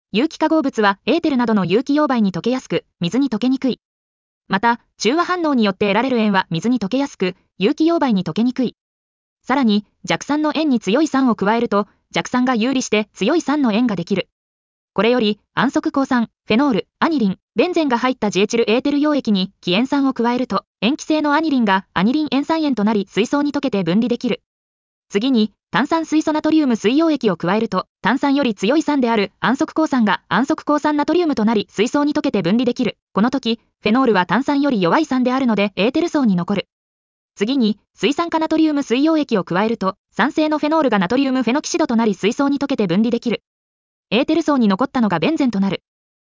• 耳たこ音読では音声ファイルを再生して要点を音読します。通学時間などのスキマ学習に最適です。
ナレーション 音読さん